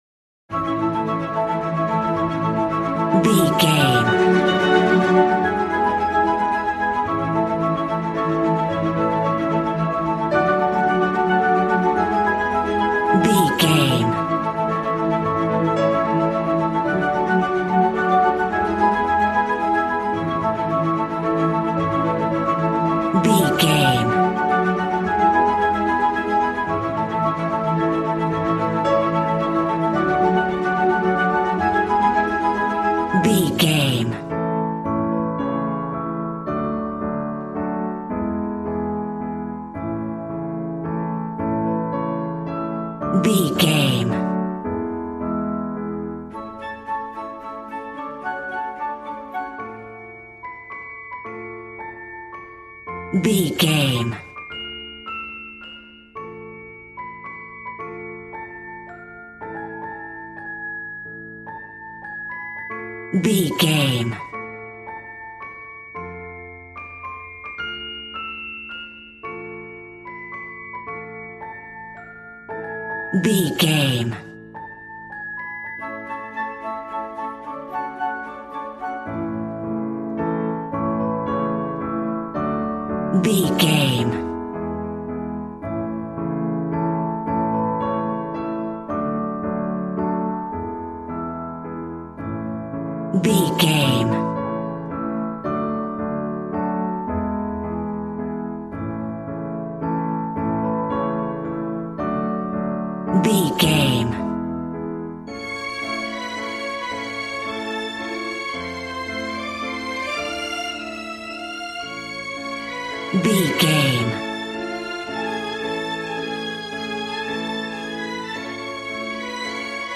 Regal and romantic, a classy piece of classical music.
Ionian/Major
strings
violin
brass